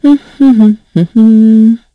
Isolet-Vox_Hum2_kr.wav